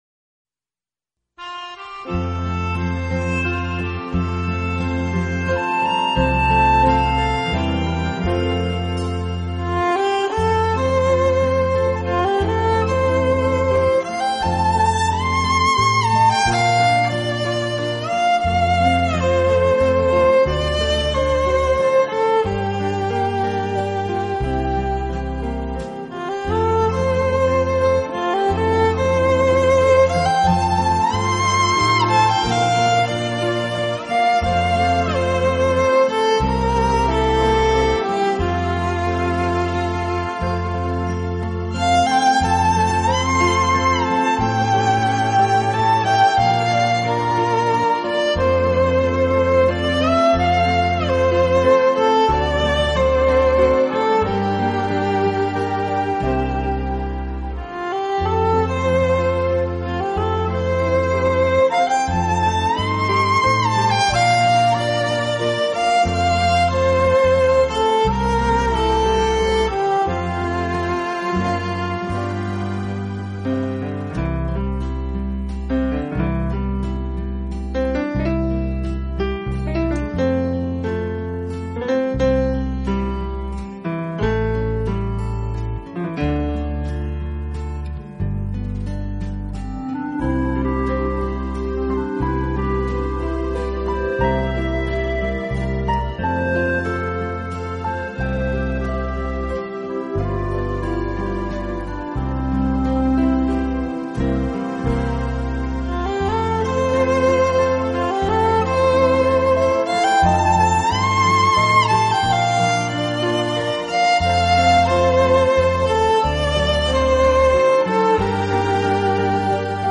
Genre ...........: Instrumental
以小提琴为主旋律，其他音乐为辅。